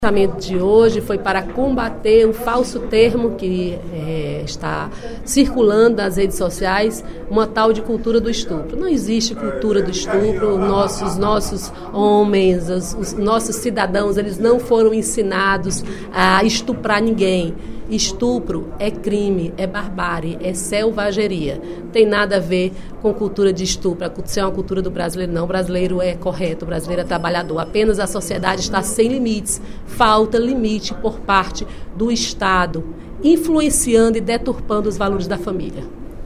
A deputada Dra. Silvana (PMDB) repudiou, durante o primeiro expediente da sessão plenária desta sexta-feira (10/06), o termo “cultura de estupro” utilizado por algumas pessoas para qualificar esse tipo de violência. Segundo a parlamentar, estupro é crime, e não pode ser enquadrado como cultura.